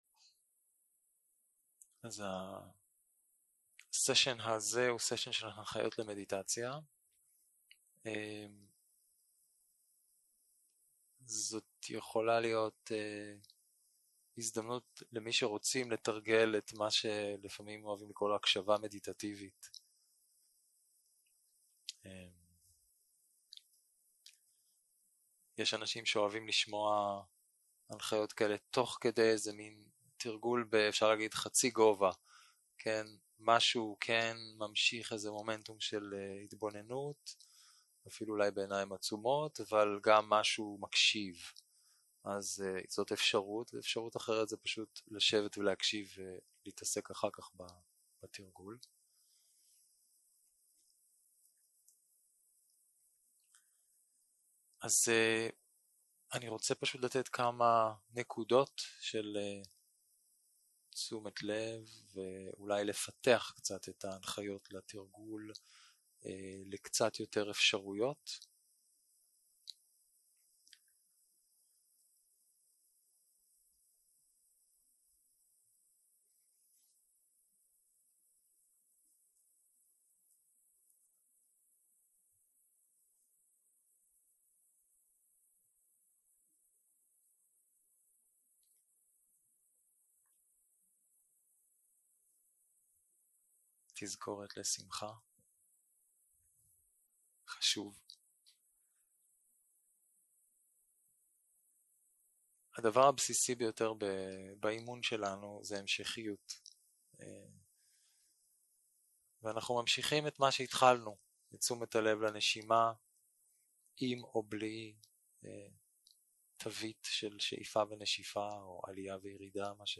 יום 4 - הקלטה 8 - בוקר - הנחיות למדיטציה